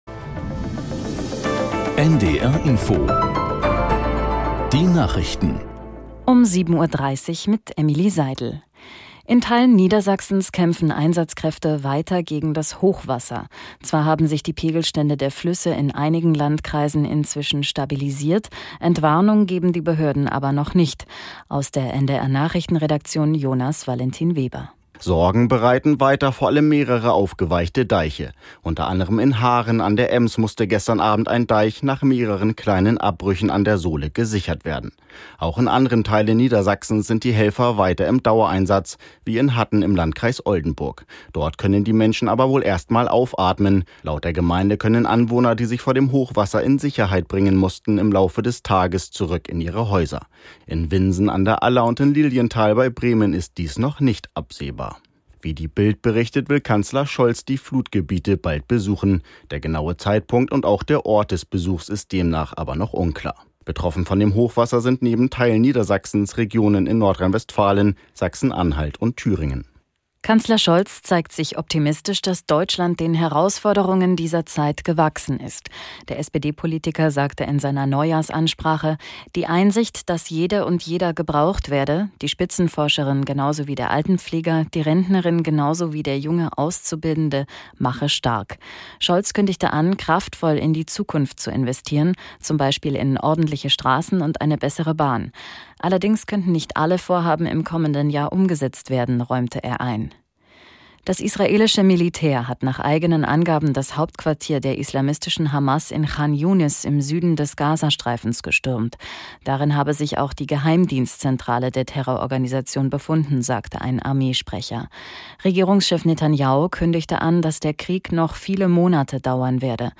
Nachrichten - 14.02.2024